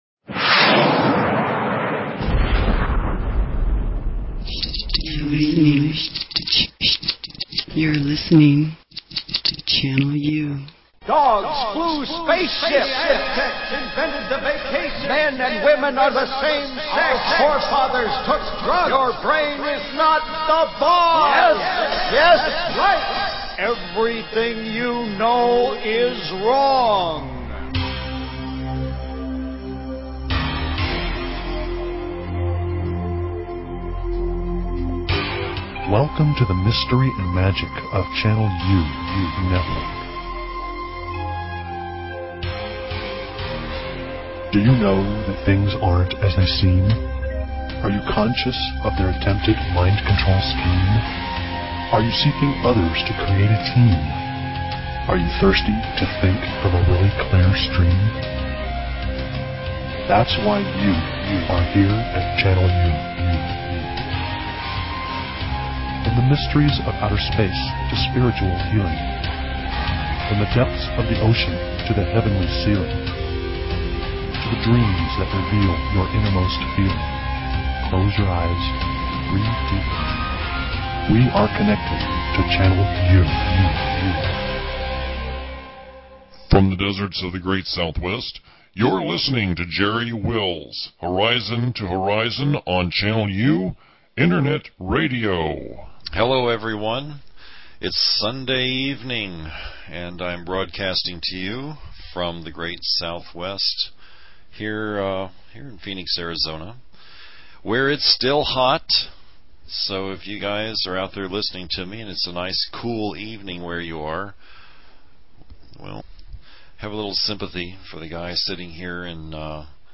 Talk Show Episode, Audio Podcast, Channel_U and Courtesy of BBS Radio on , show guests , about , categorized as